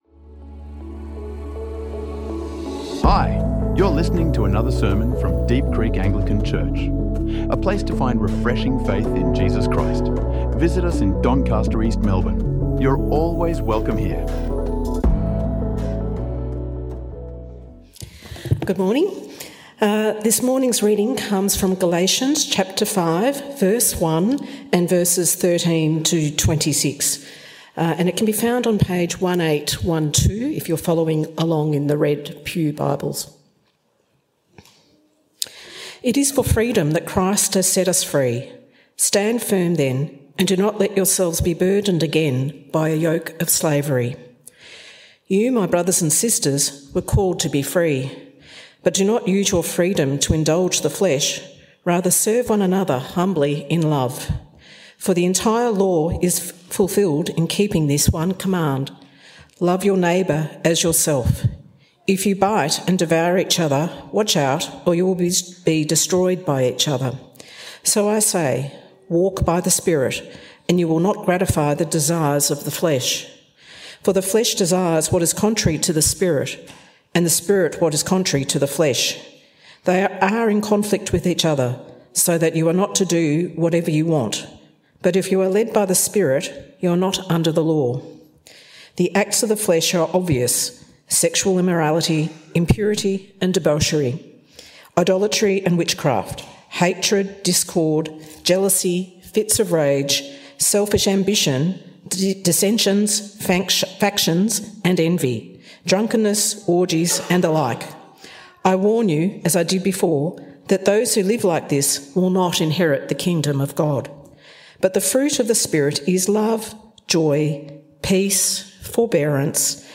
Freedom Can Be Seen | Sermons | Deep Creek Anglican Church